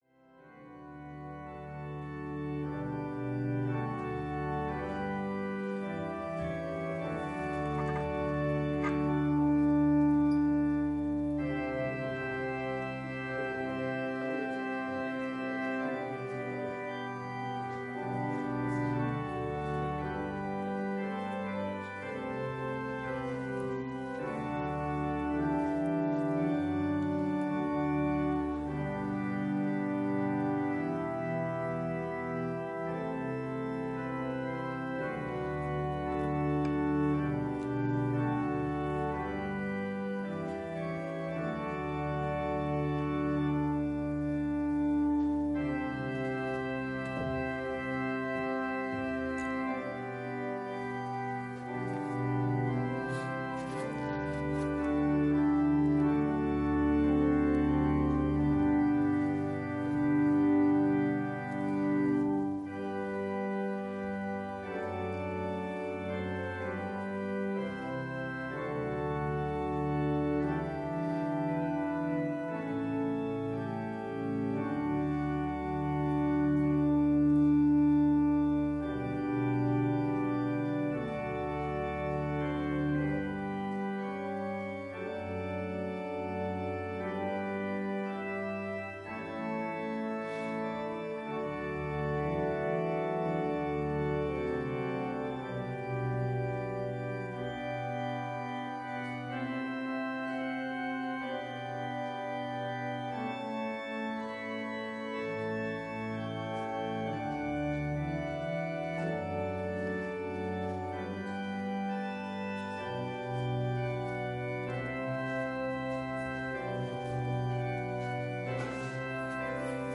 礼拝音源(28.5MB)